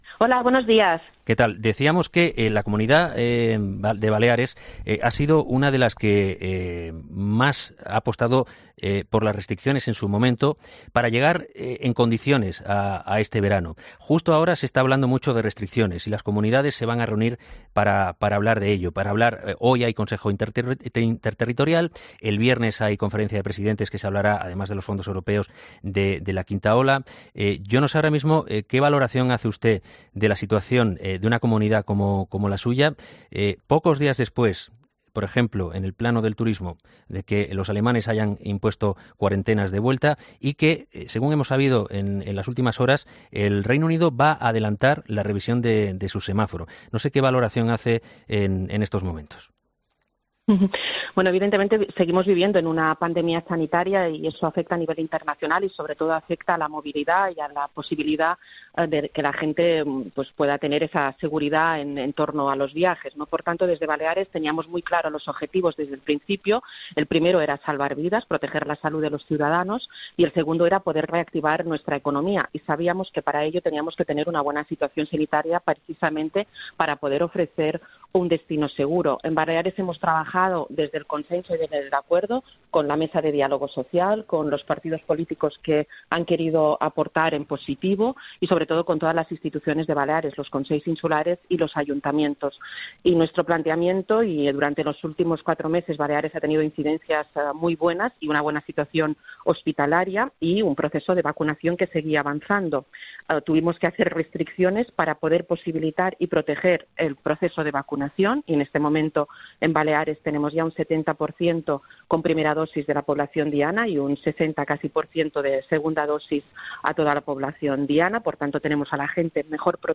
La presidenta de las Islas Baleares ha atendido a los micrófonos de 'Herrera en COPE' para analizar la actualidad sanitaria que afecta especialmente...